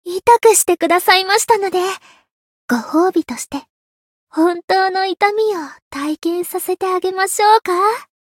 灵魂潮汐-安德莉亚-问候-不开心.ogg